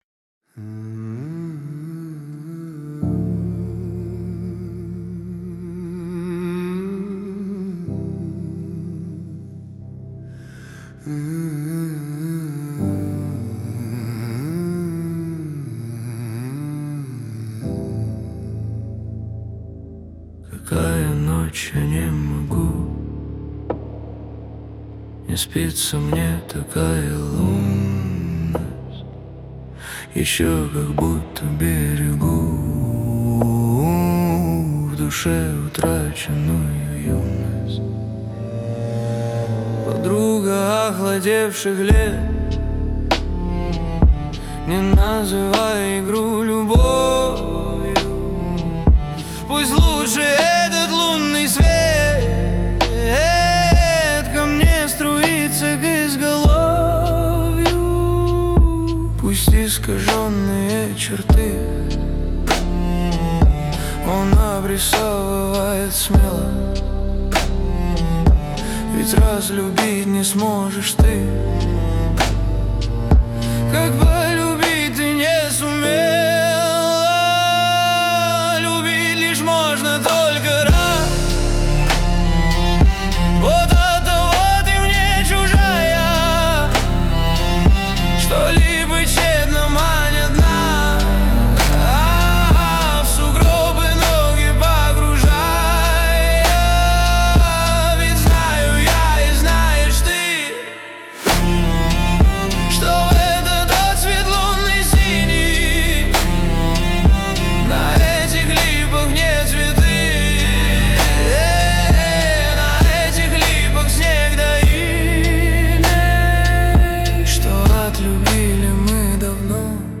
13 декабрь 2025 Русская AI музыка 71 прослушиваний